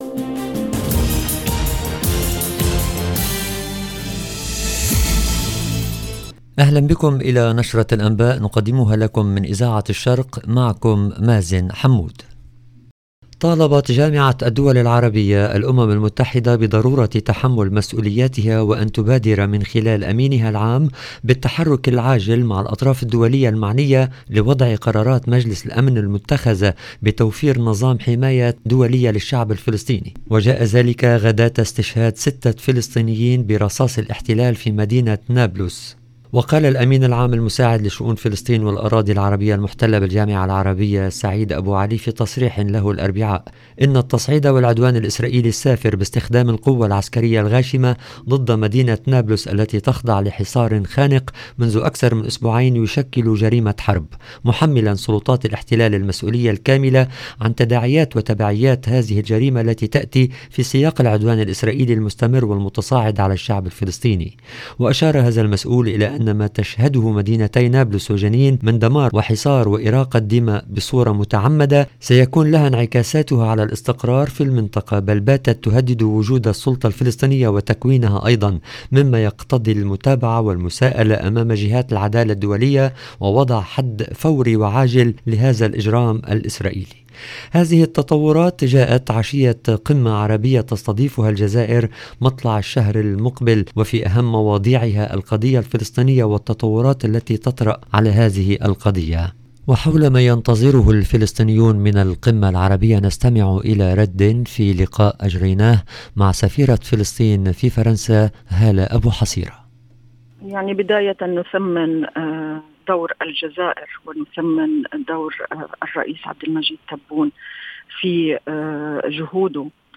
LE JOURNAL EN LANGUE ARABE DU SOIR DU 26/10/22